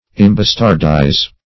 Imbastardize \Im*bas"tard*ize\
imbastardize.mp3